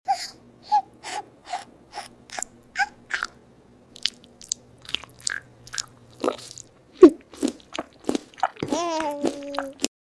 Kiwi Eating 🥝 ASMR Make sound effects free download